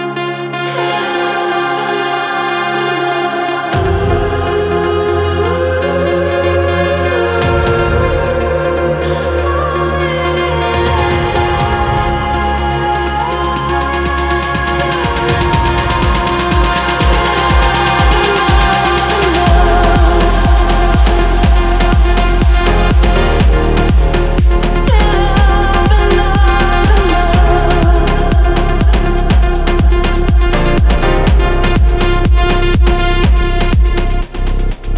Жанр: Африканская музыка